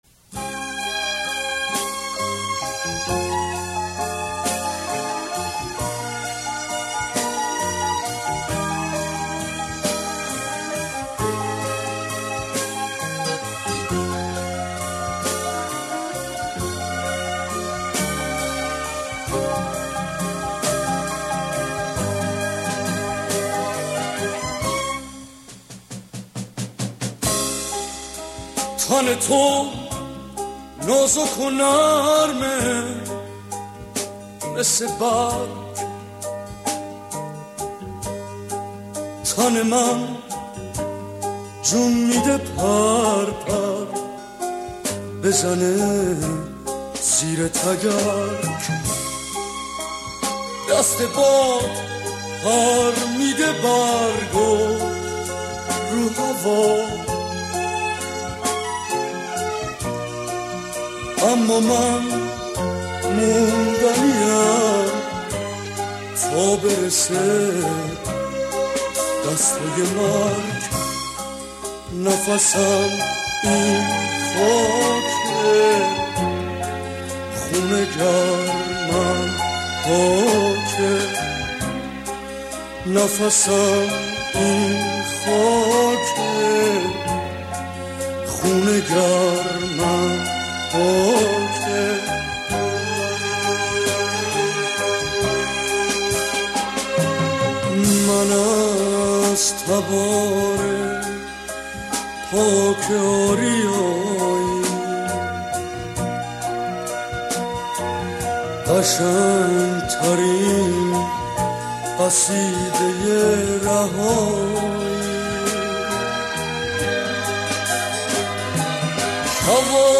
آهنگ قدیمی
غمگین و احساسی قدیمی